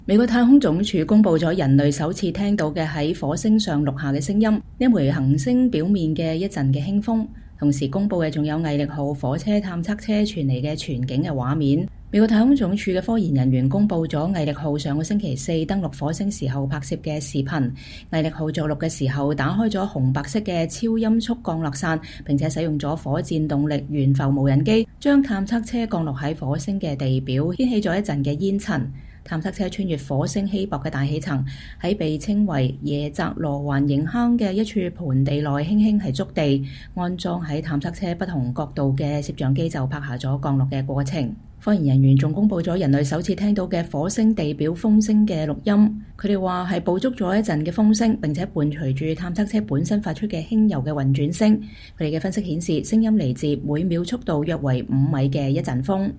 美國太空總署(NASA)公佈了人類首次聽到的在火星上錄下的聲音 - 這顆行星表面的一陣輕風。